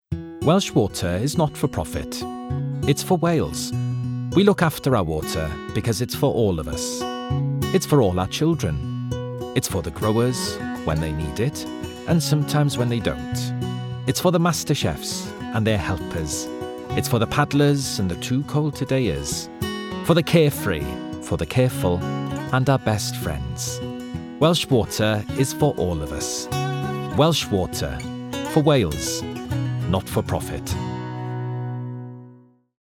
20/30's Welsh, Expressive/Warm/Natural
Commercial Showreel Metro Bank Tesco